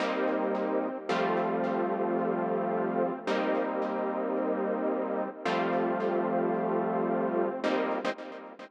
30 Synth PT4.wav